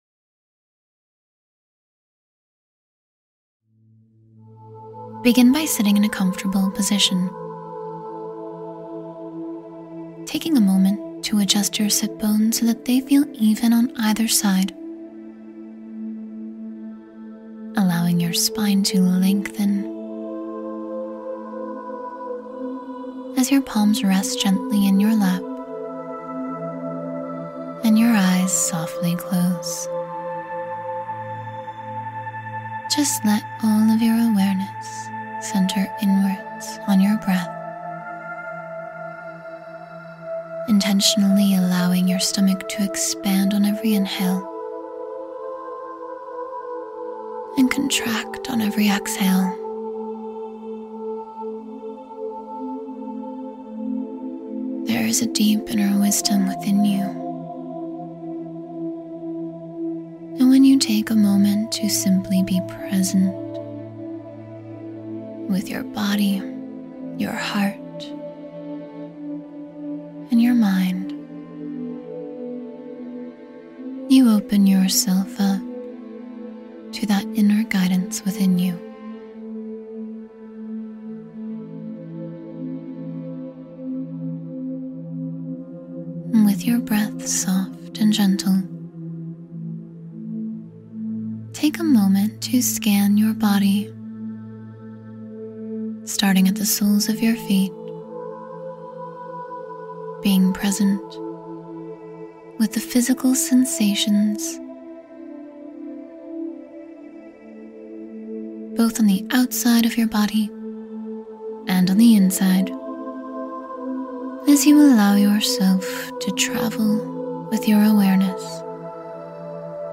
Gratitude Meditation — Feel the Power of Positive Emotions